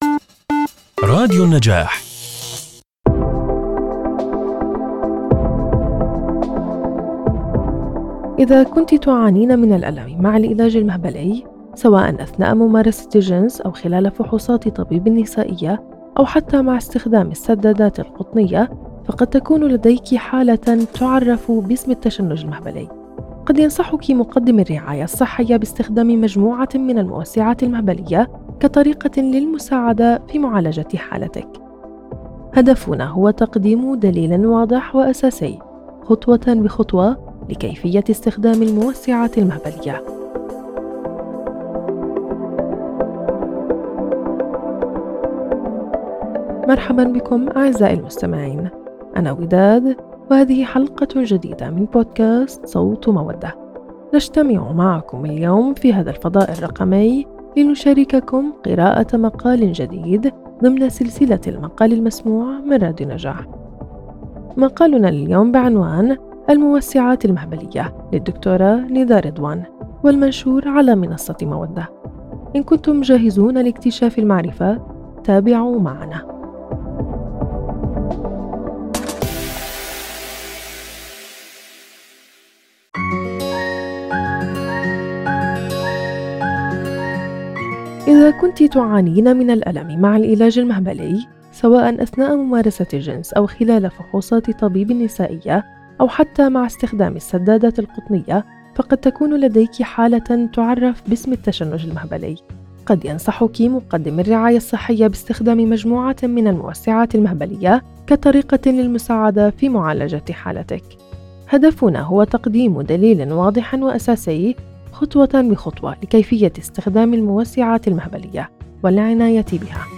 في هذا البودكاست، ننقل لكم المقالات المتنوعة والغنية المنشورة على منصة مودة إلى عالم الصوت، مما يوفر تجربة استماع ممتعة وملهمة.